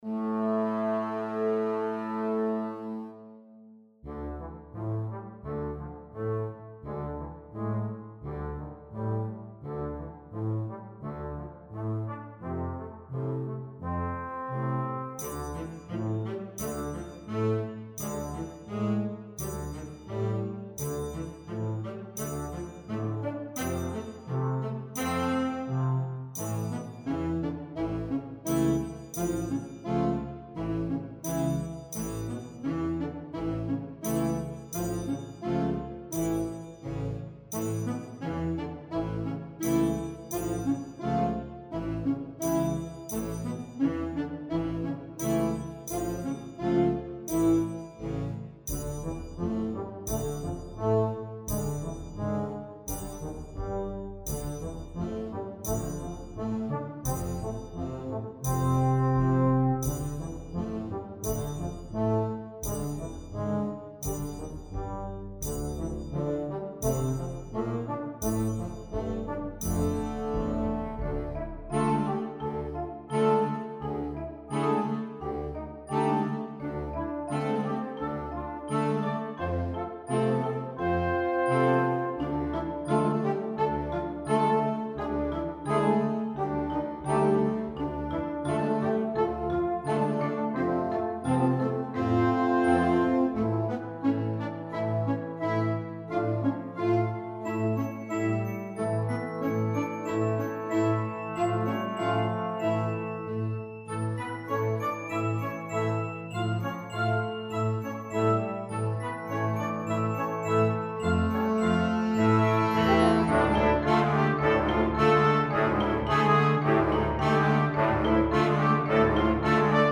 Rehearsal Track
(For Concert Band)